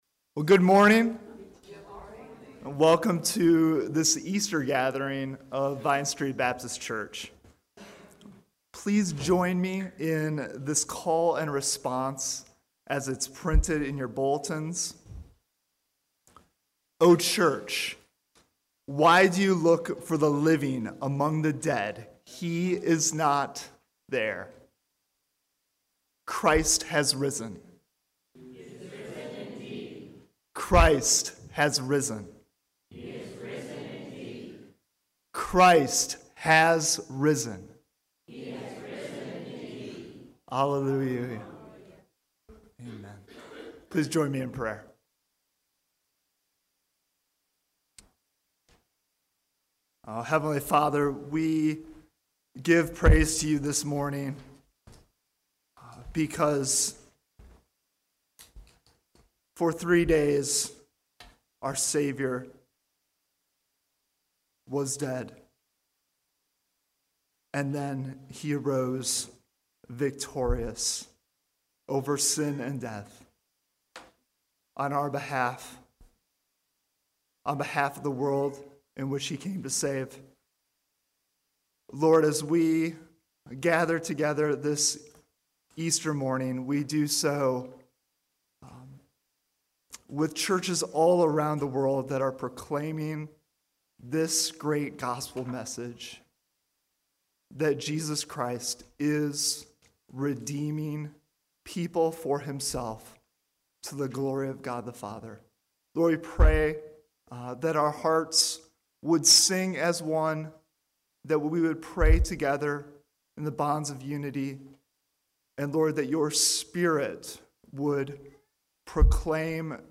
April 17 Worship Audio – Full Service